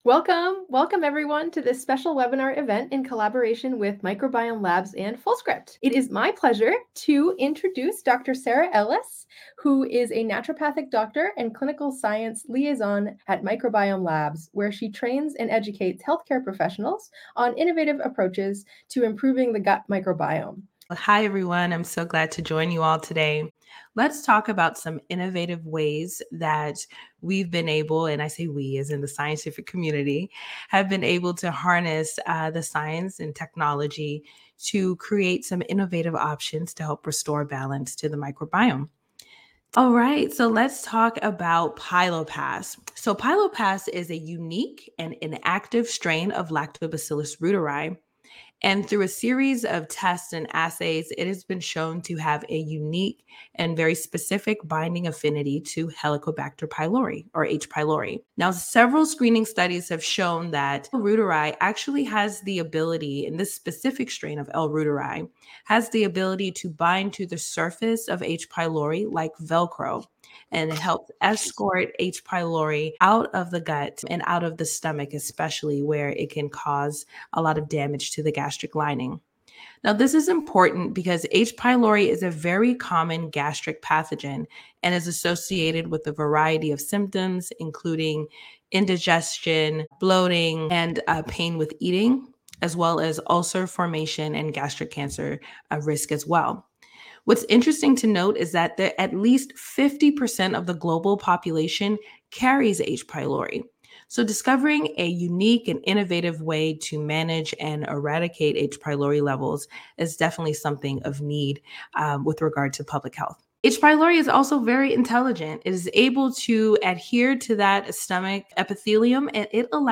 MS for an insightful discussion on innovative approaches addressing gut dysbiosis.
Audio highlights